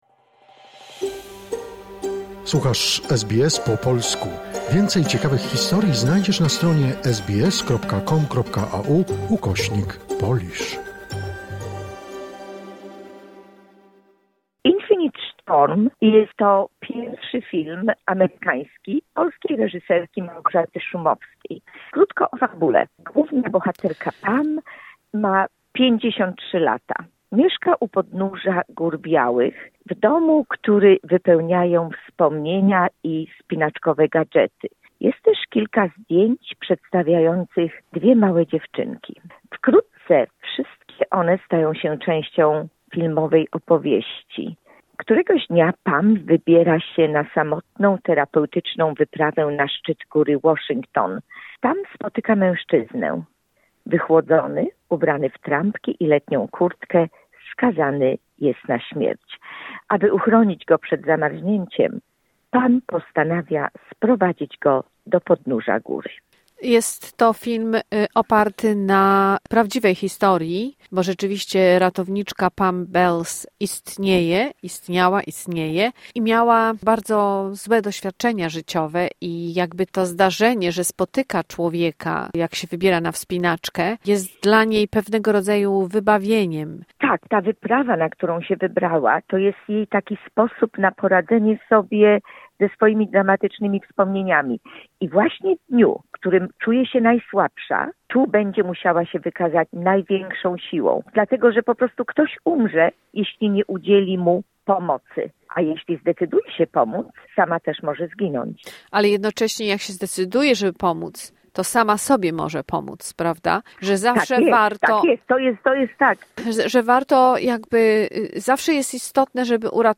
"Infinite storm" - recenzja filmowa